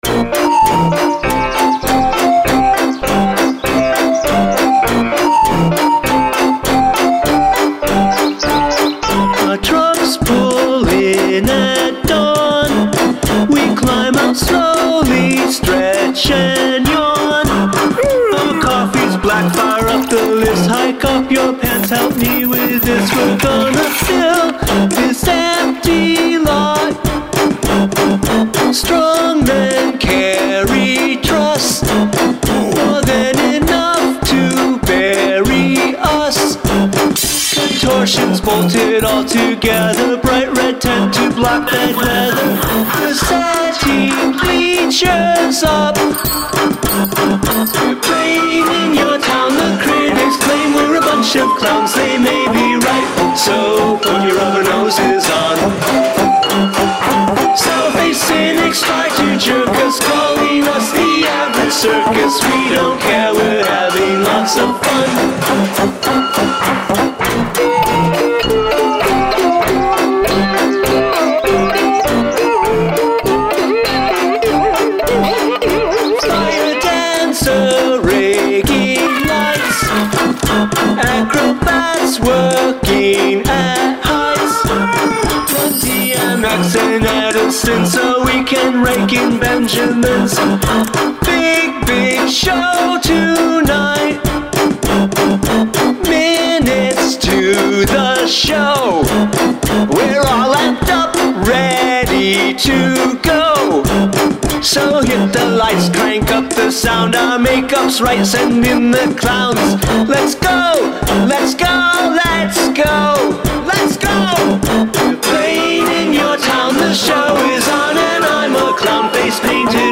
Use something from your garbage bin as an instrument